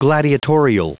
Prononciation du mot gladiatorial en anglais (fichier audio)
Prononciation du mot : gladiatorial